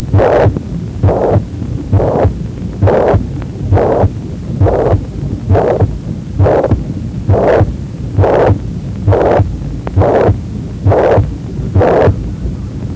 Holosystolic หรือ Pansystolic murmur   เป็นเสียง murmur ที่ฟังได้ตลอดช่วงการบีบตัว